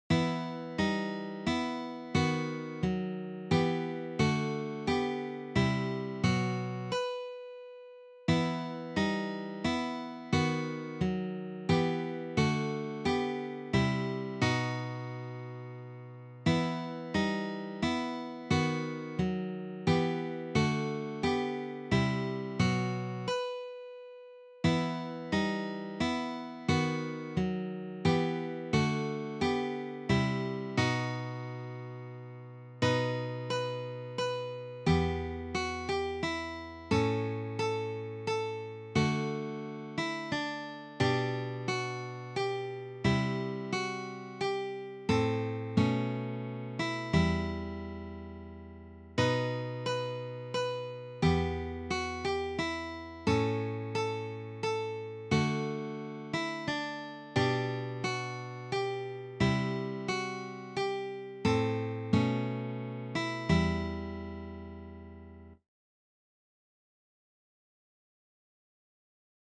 arranged for three guitars
This Baroque selection is arranged for guitar trio.